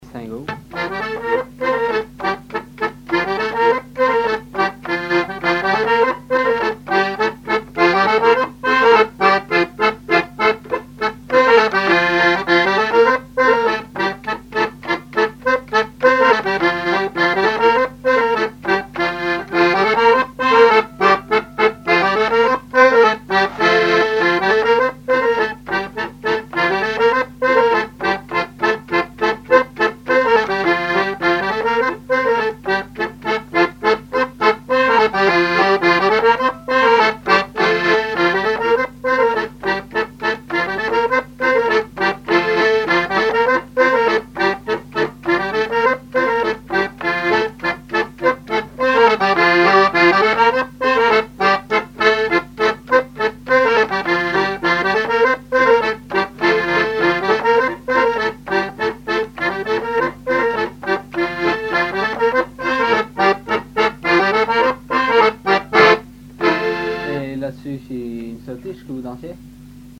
Chants brefs - A danser
danse : scottich trois pas
Pièce musicale inédite